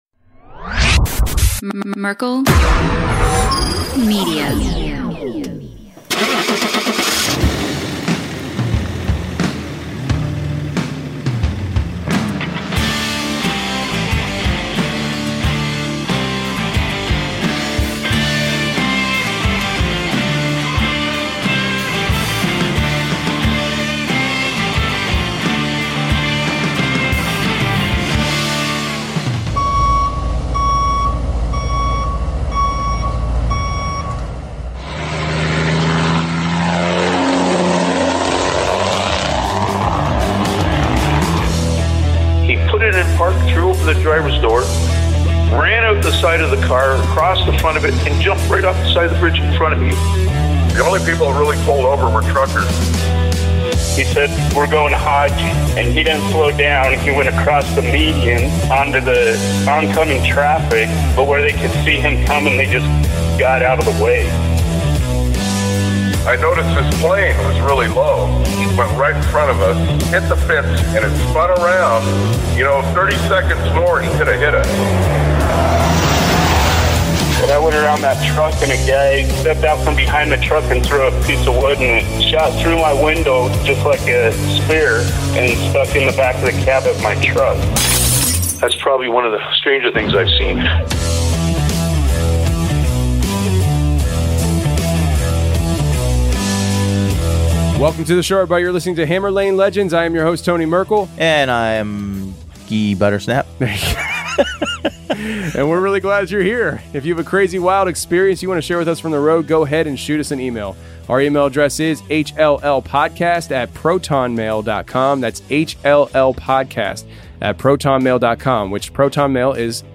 On Episode 52: Call-In Show, we have... you guessed it... a call-in show!
We hope you enjoy these one-sided conversations, and feel free to leave us your own voicemail at 515-585-MERK (6375)!